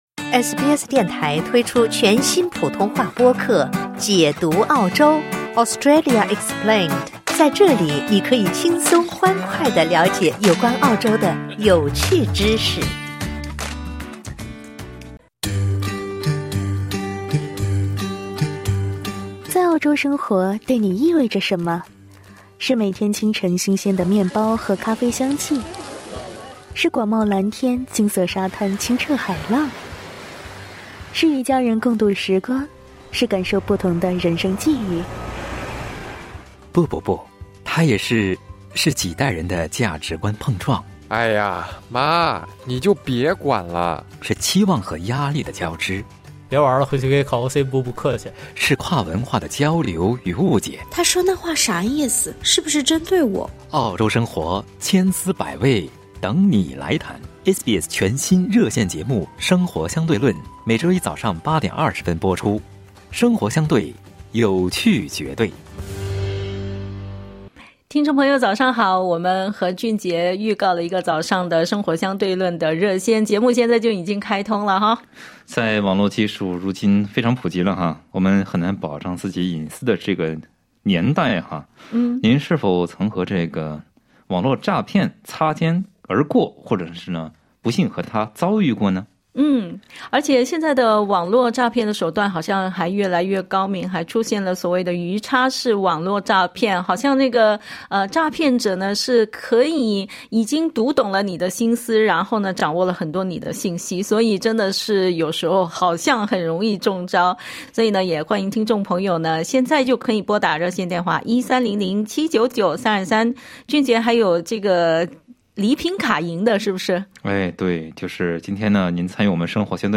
欢迎您点击音频收听经验分享 SBS中文普通话热线节目《生活相对论》每周一早上8：20左右播出。